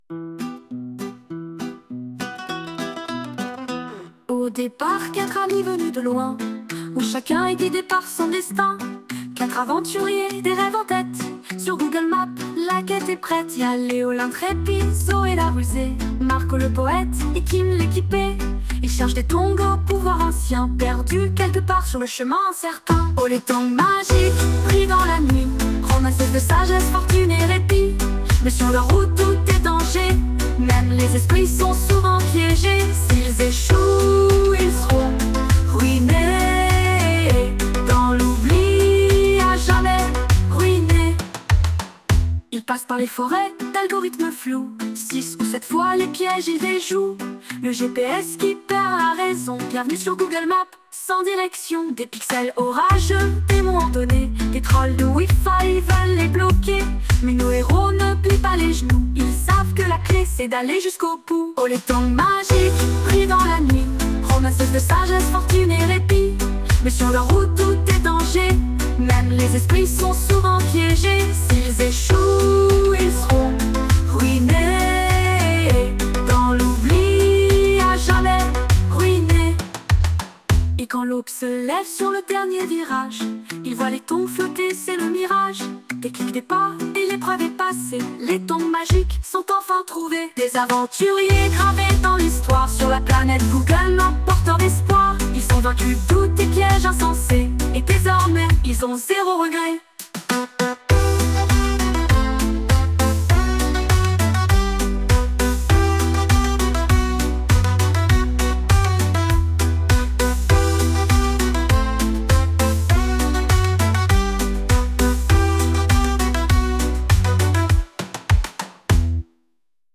Chanson :